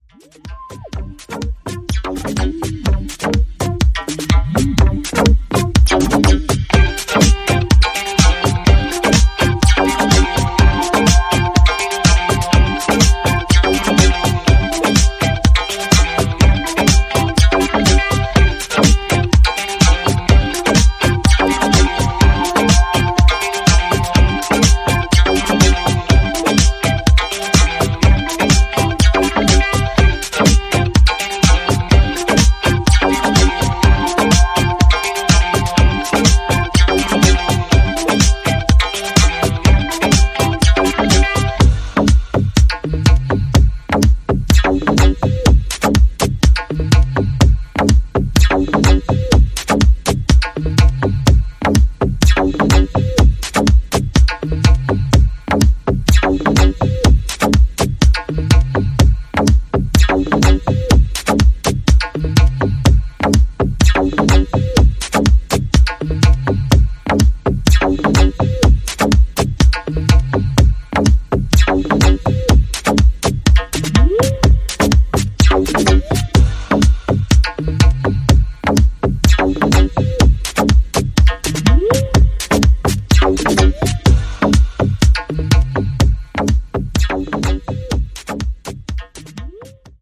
Three killer disco classics re-worked by a legend.